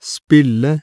Hvis plosiven uttales etter en blir ikke plosiven aspirert, som i f.eks. spille:
Bølgeforma til en sørøstnorsk uttale av spille .